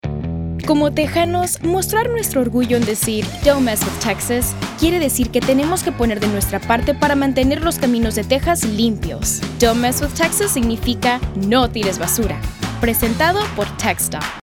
RADIO:15 SPA